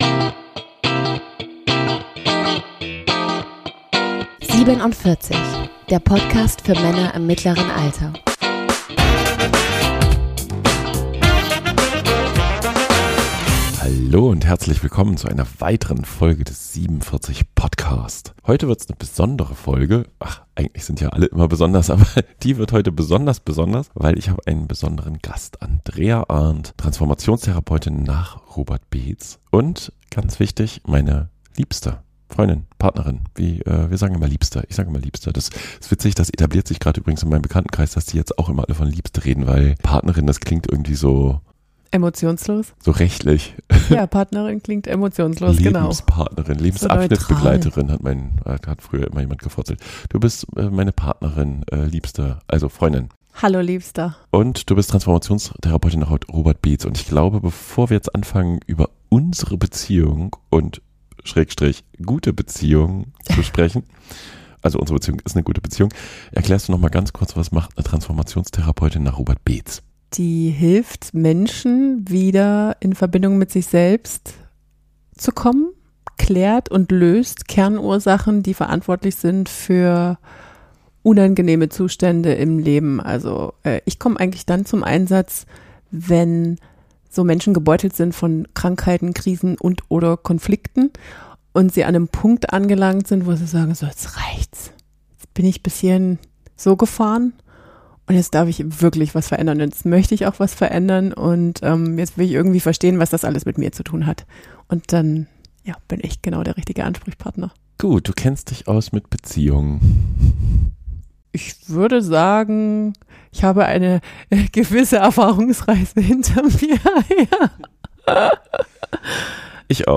Spoiler-Hinweis: In dieser Folge wird sehr viel gelacht!